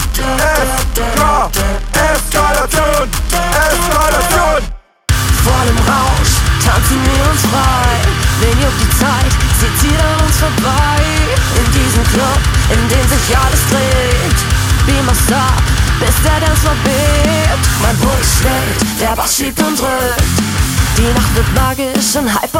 Pioniere eines neuen Sounds: Popcore.